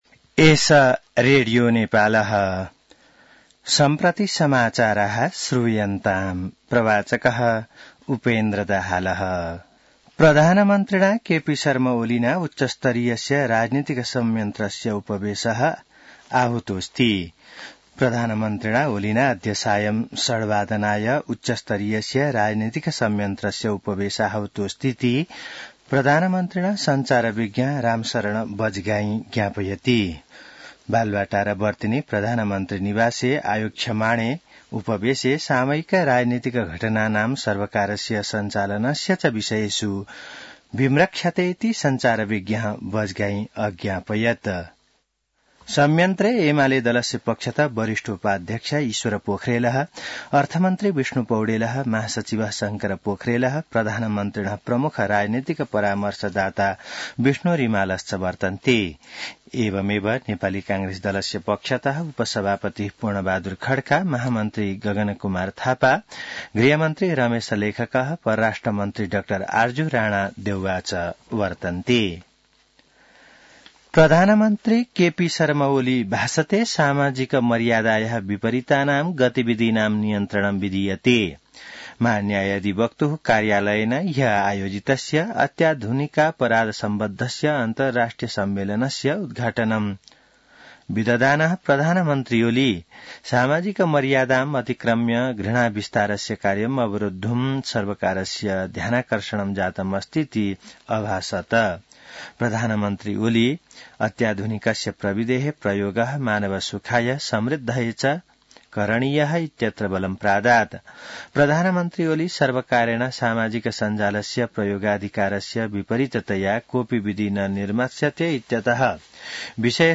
संस्कृत समाचार : १७ फागुन , २०८१